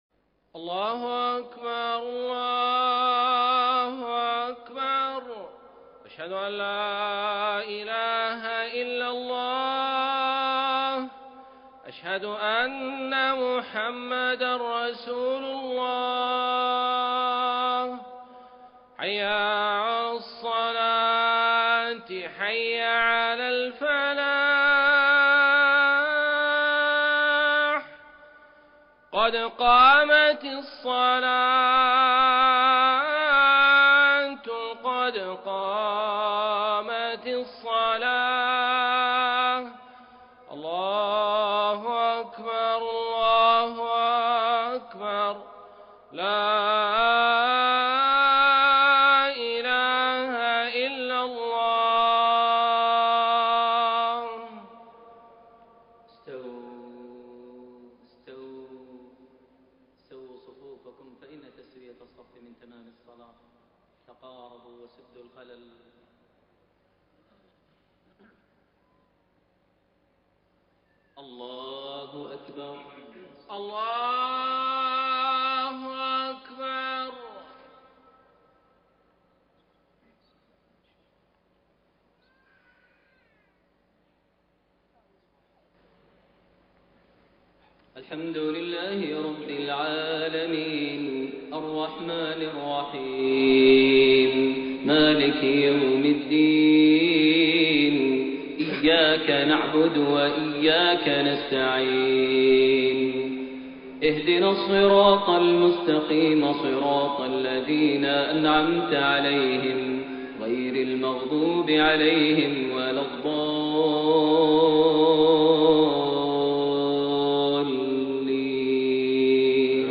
صلاة المغرب 5-2-1433هـ سورة القيامة > 1433 هـ > الفروض - تلاوات ماهر المعيقلي